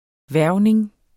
Udtale [ ˈvæɐ̯wneŋ ]